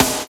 Index of /90_sSampleCDs/Roland - Rhythm Section/KIT_Drum Kits 4/KIT_Hard Core
SNR EKO S01L.wav